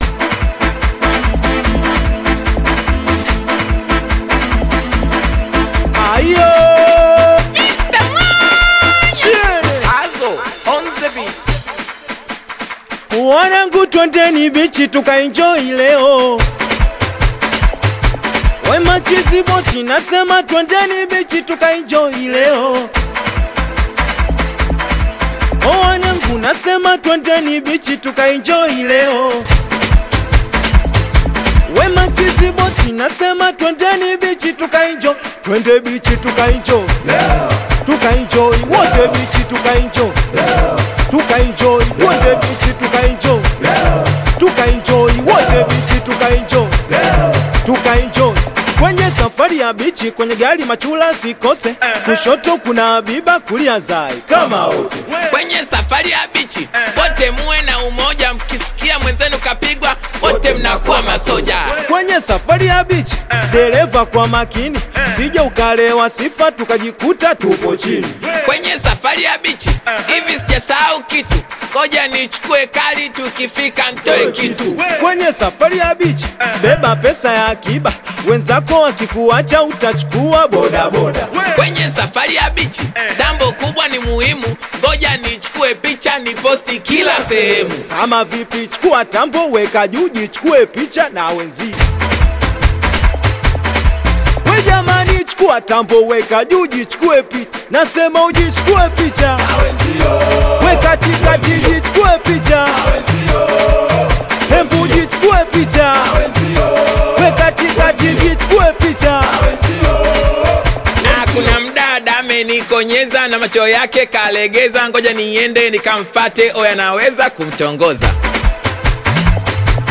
Bongo Flava
Singeli